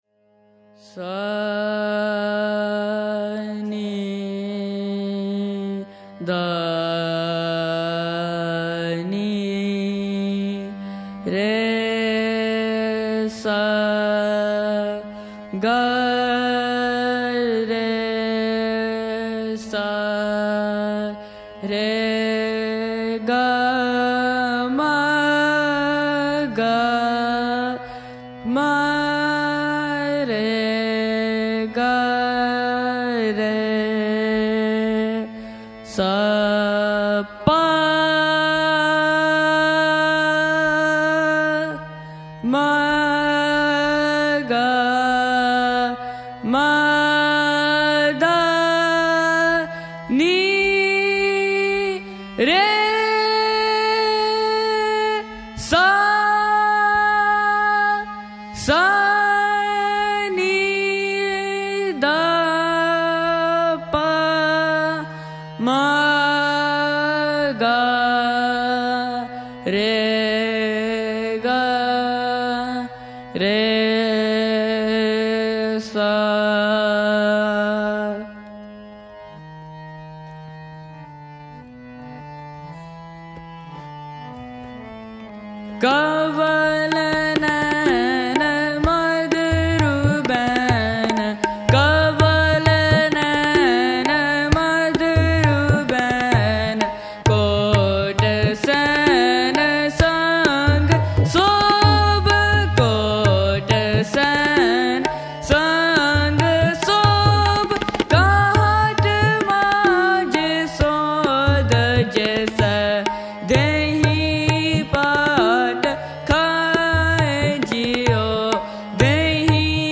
High Definition recordings of contemporary Gurmat Sangeet
at Scarborough Gurdwara on May 21 2011